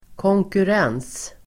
Uttal: [kångkur'ang:s (el. -'en:s)]